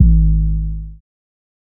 SouthSide Kick (20).wav